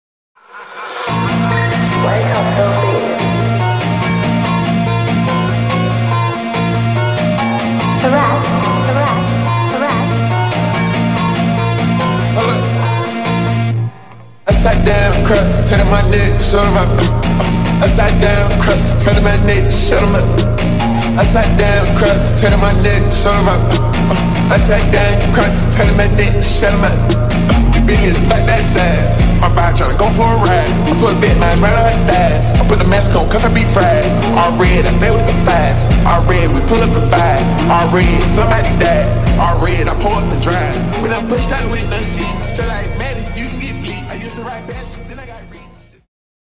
(𝑮𝒖𝒊𝒕𝒂𝒓 𝑹𝒆𝒎𝒊𝒙)